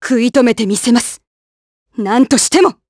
Glenwys-Vox_Skill6_jp.wav